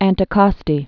(ăntĭ-kôstē, -kŏstē)